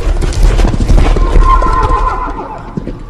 headless_horseman.ogg